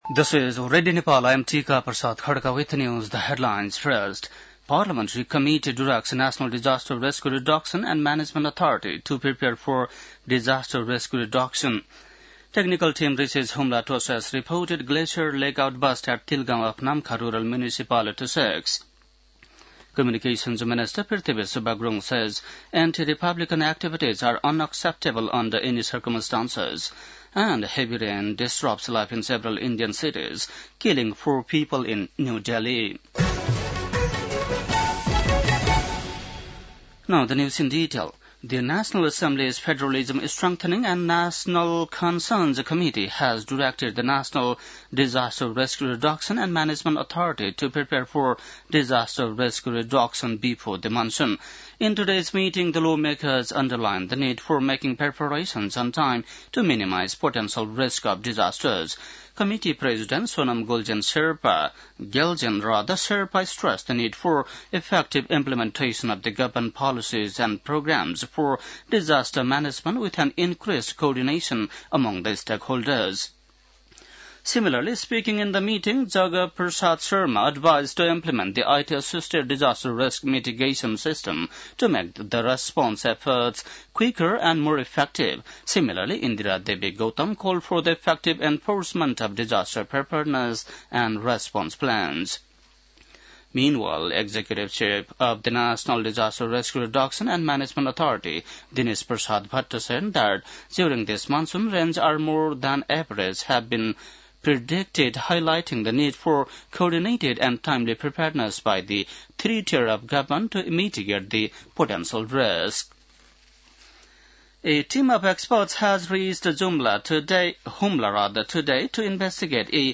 बेलुकी ८ बजेको अङ्ग्रेजी समाचार : ८ जेठ , २०८२
8-pm-english-news-.mp3